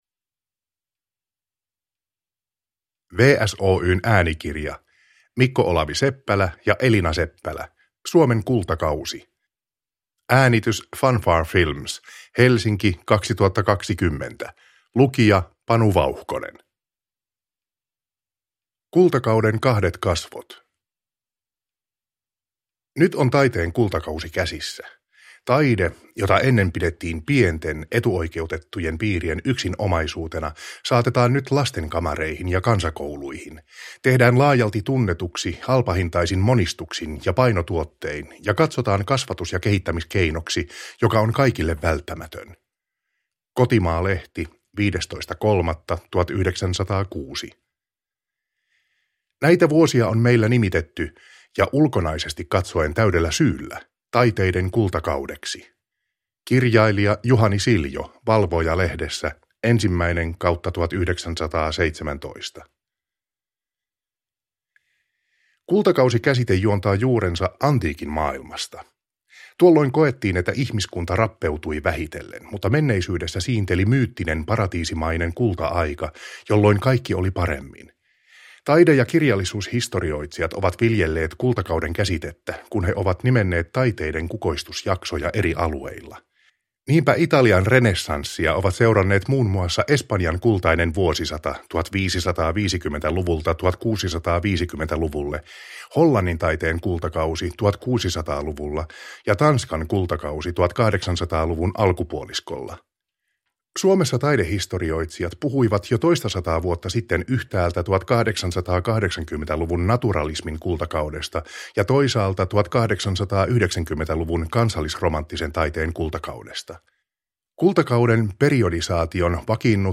Suomen kultakausi – Ljudbok – Laddas ner